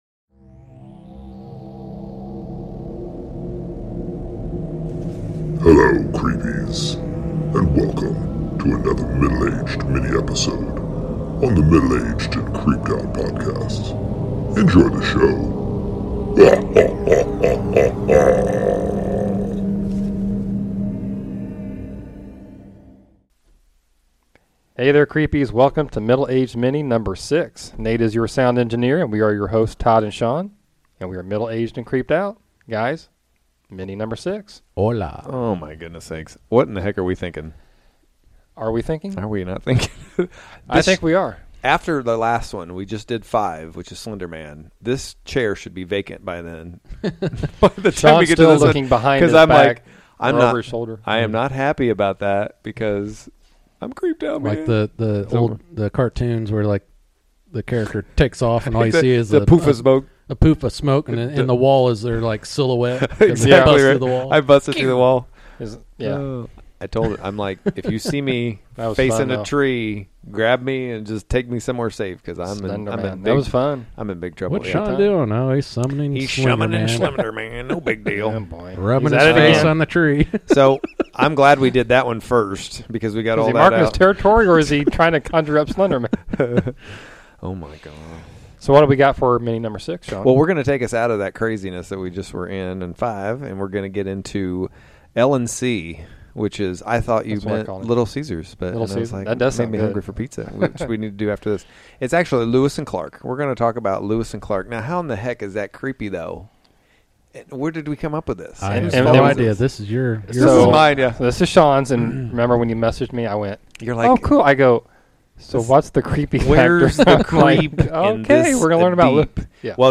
The guys discuss the questionable history and events surrounding the demise of this early American hero